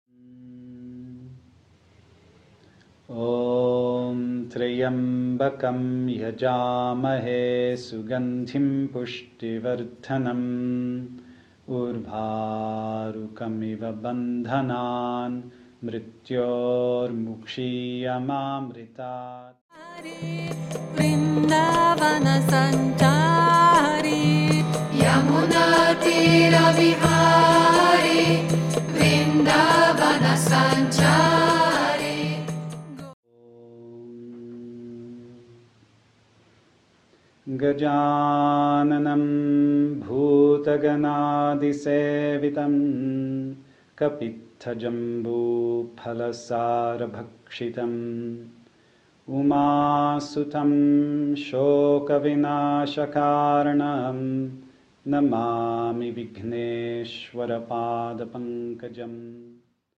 A felvétel a magyar Rádió 8-as stúdiójűban készült.